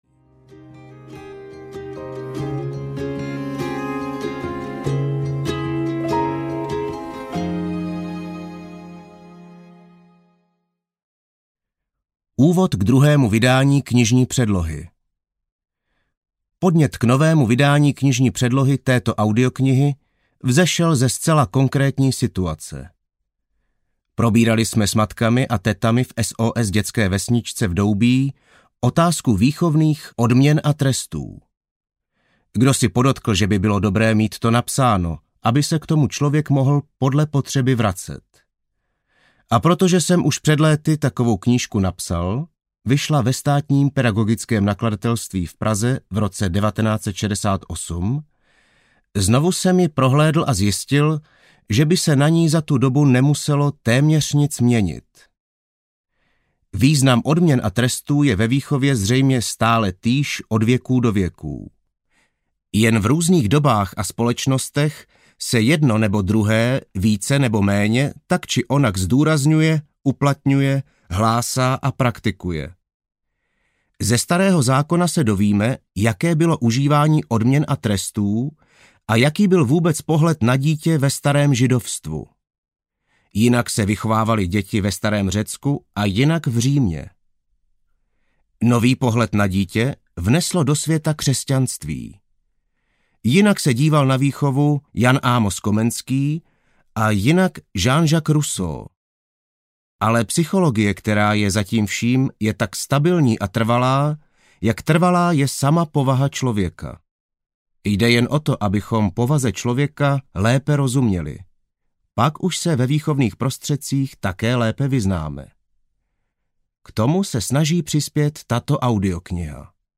Po dobrém, nebo po zlém audiokniha
Ukázka z knihy